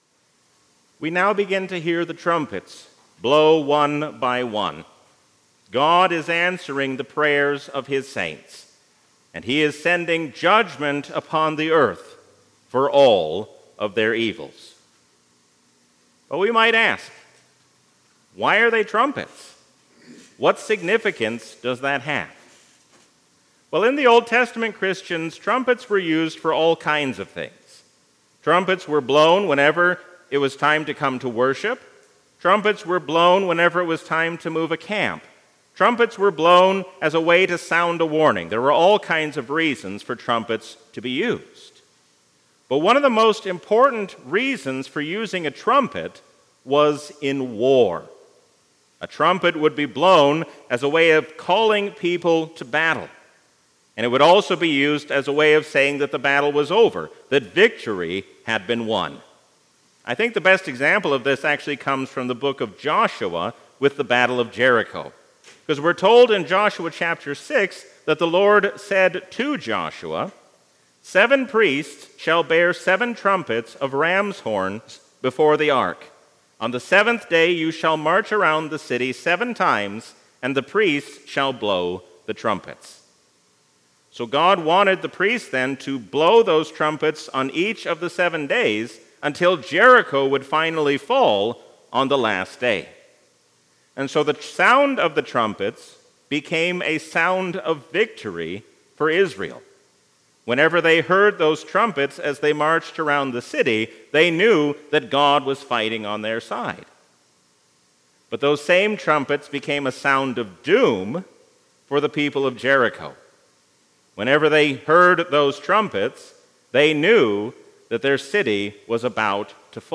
A sermon from the season "Trinity 2023." Do not turn to the things of this world, because they cannot help. God alone is the Lord, and there is no other!